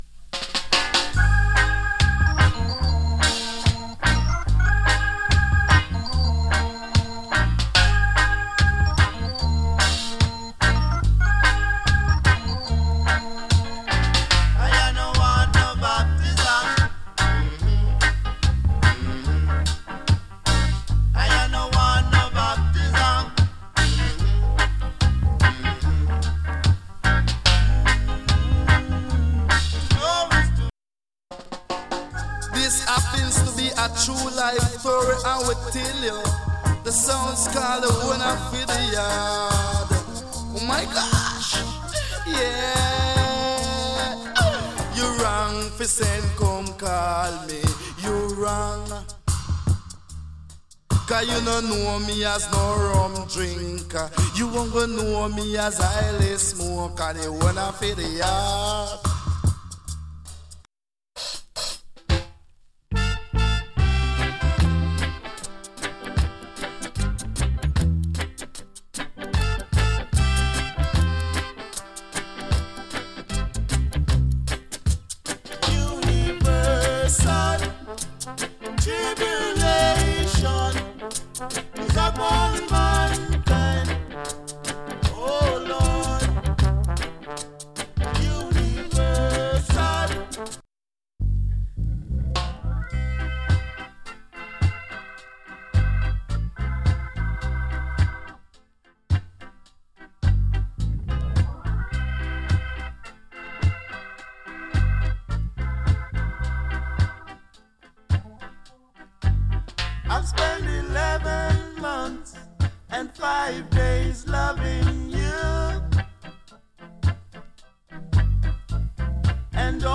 EARLY REGGAE 〜 REGGAE